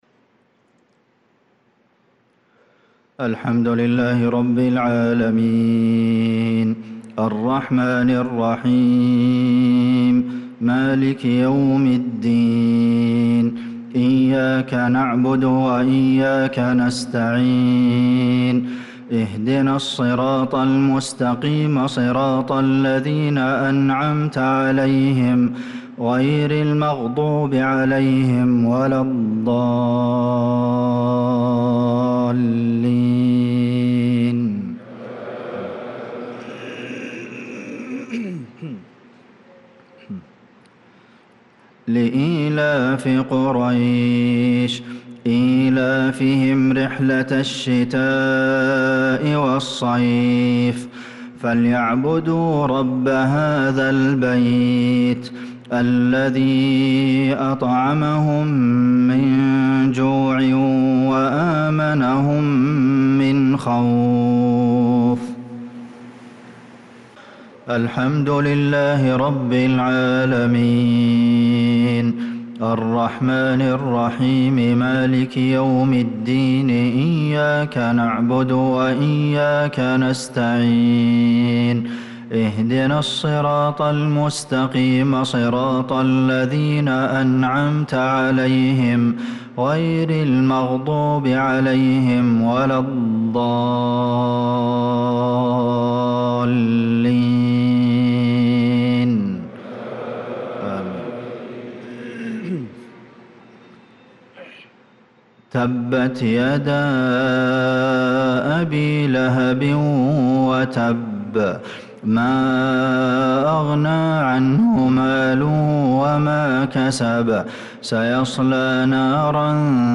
صلاة المغرب للقارئ عبدالمحسن القاسم 5 ذو الحجة 1445 هـ
تِلَاوَات الْحَرَمَيْن .